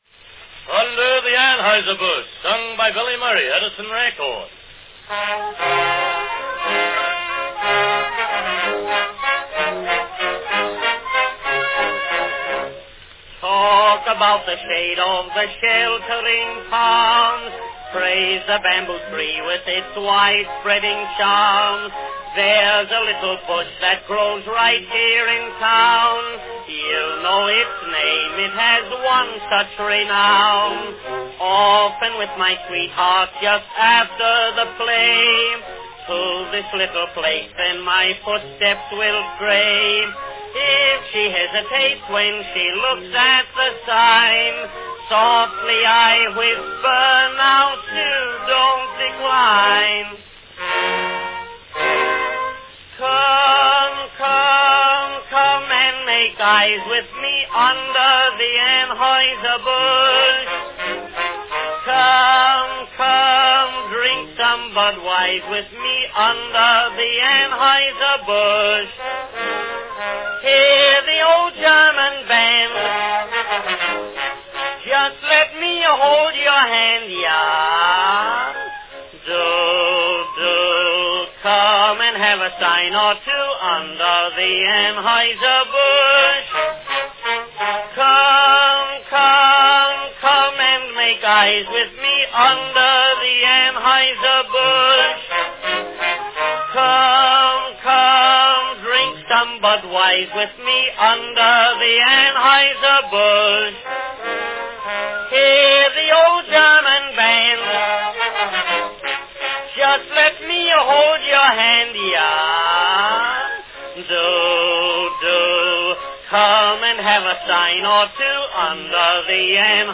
An early recording of the hit comic song Under the Anheuser Bush, sung by Billy Murray.
Company Edison's National Phonograph Company
Category Waltz song
Performed by Billy Murray
Announcement "Under the Anheuser Bush, sung by Billy Murray.  Edison record."
It has been sung by Billy Murray, with unusual care and with splendid enunciation.   The song has a waltz air, and the singer is accompanied by the Edison Symphony Orchestra.